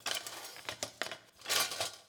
SFX_Cooking_Utensils_01_Reverb.wav